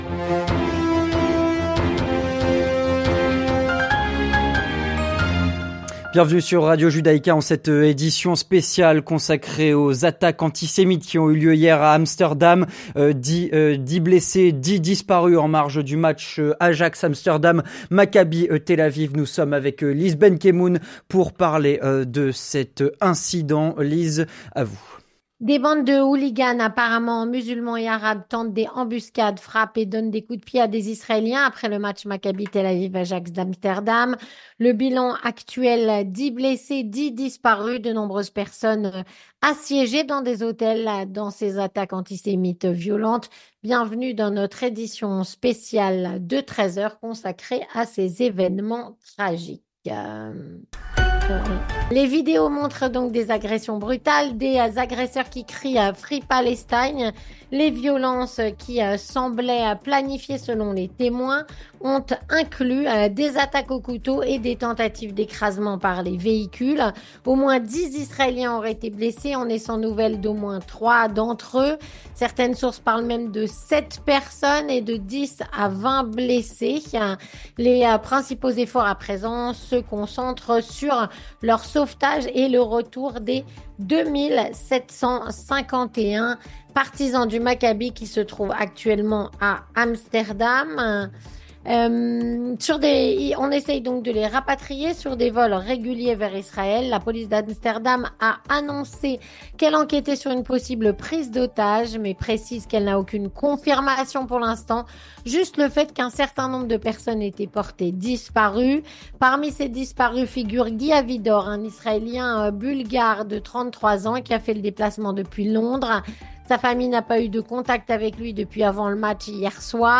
Breaking News - Des supporters israéliens attaqués à Amsterdam, après le match Ajax - Maccabi Tel-Aviv (10 blessés, 10 disparus).
Édition Spéciale du Journal